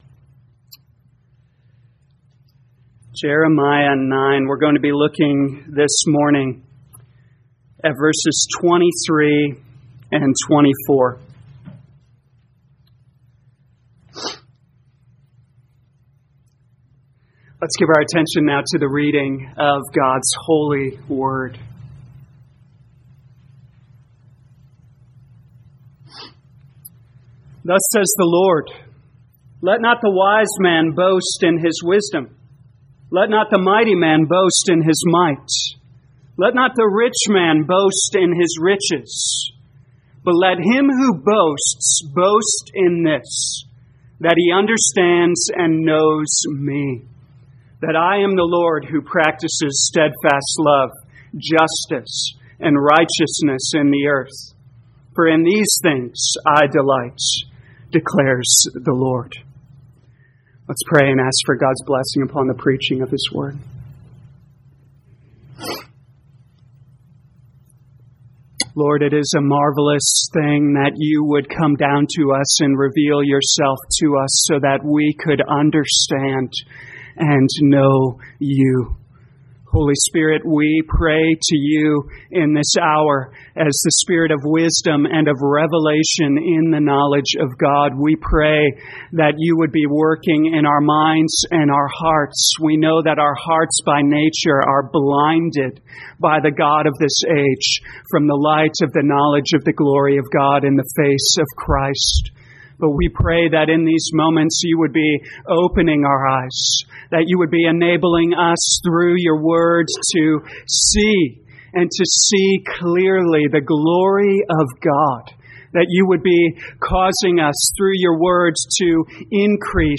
2022 Jeremiah Knowing God Morning Service Download